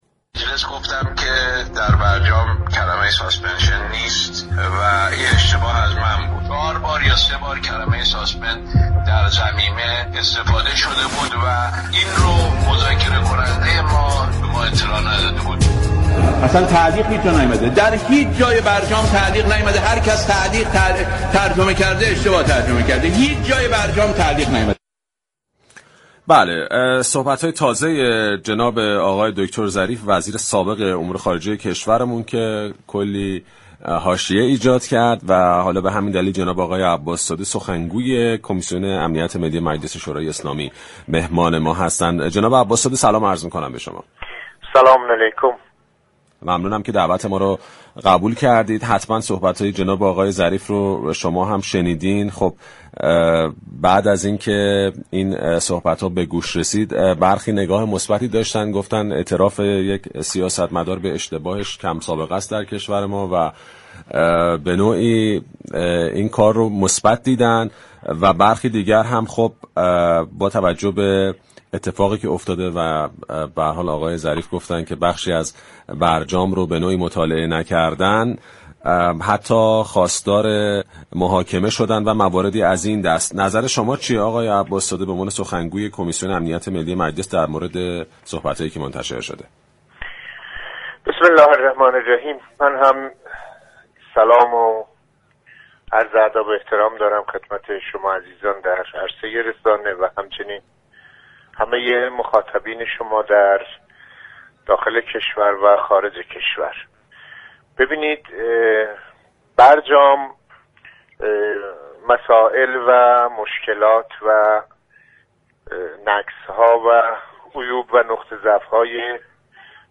سخنگوی كمیسیون امنیت ملی مجلس: بعید می‌دانم ظریف از واژه تعلیق در برجام اطلاع نداشته باشد+فایل صوتی